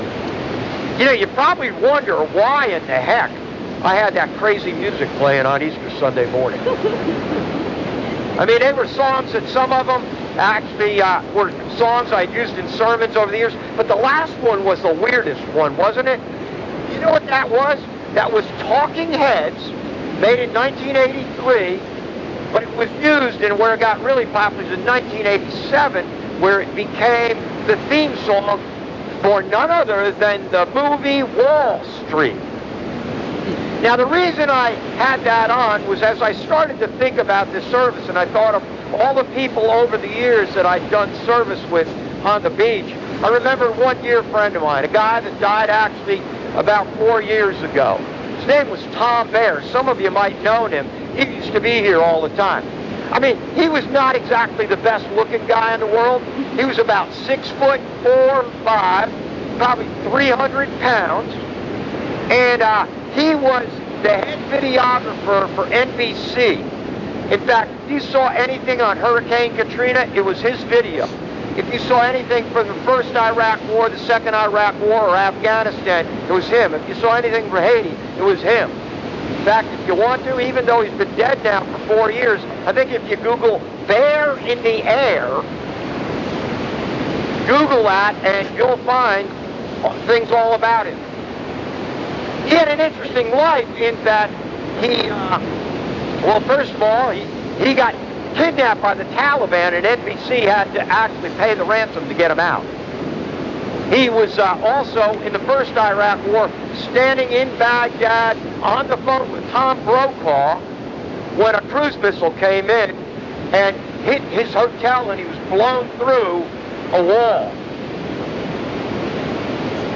Easter Sunrise Service
Don't mind the wind and the waves!